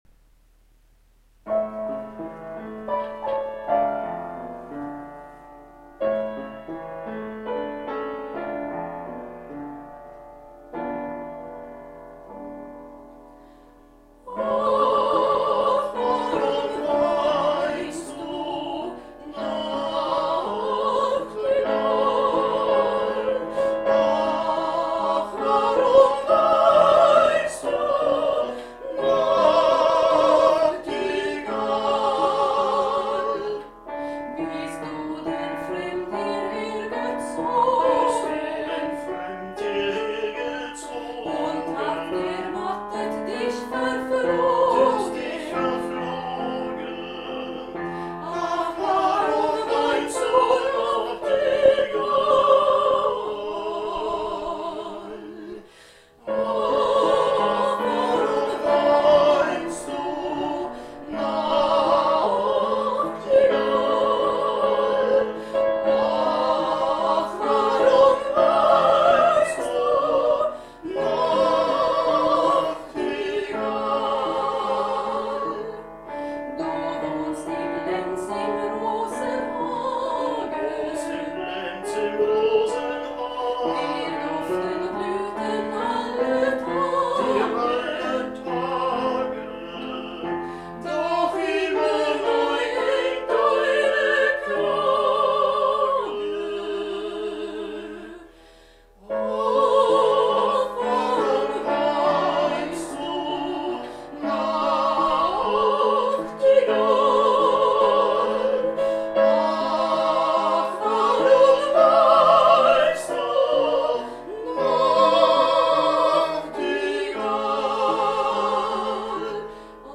Two duets on Turkish poems
für Sopran und Tenor
for soprano and tenor